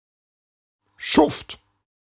Ääntäminen
US : IPA : /ˈdɑɡ/